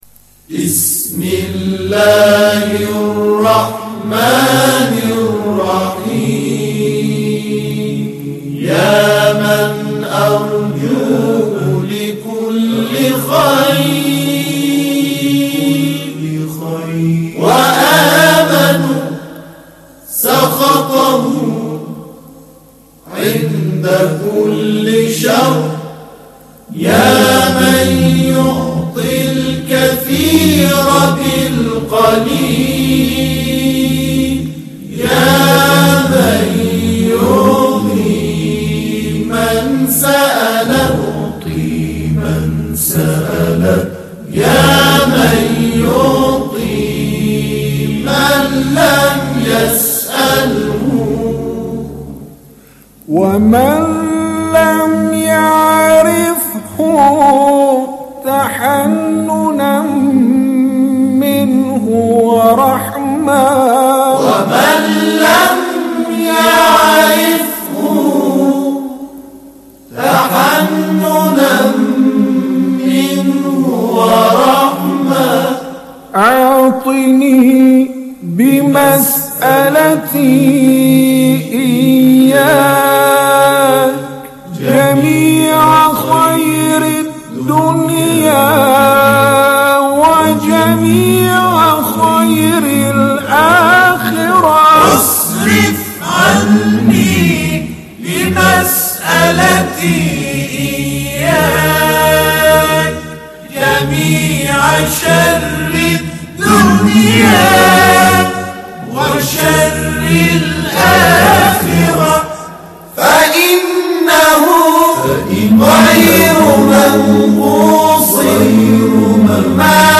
نغمة الحجاز يامن ارجوه - لحفظ الملف في مجلد خاص اضغط بالزر الأيمن هنا ثم اختر (حفظ الهدف باسم - Save Target As) واختر المكان المناسب